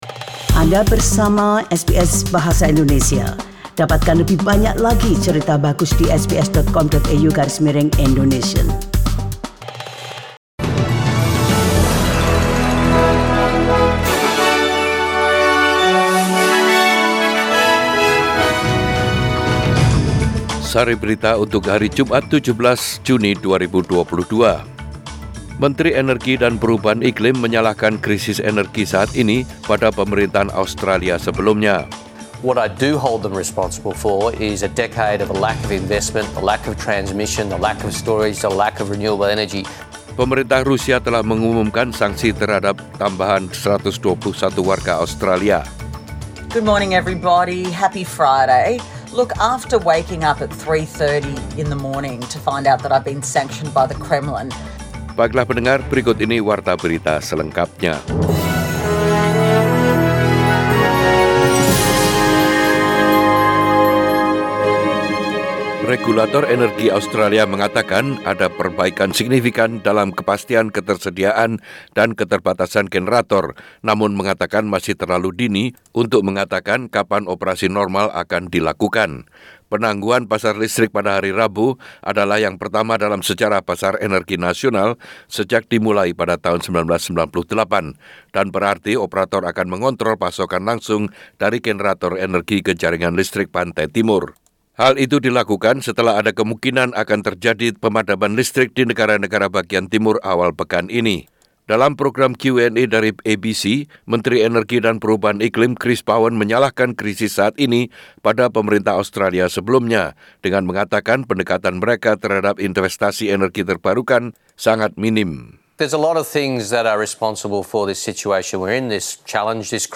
Warta Berita Radio SBS Program Bahasa Indonesia.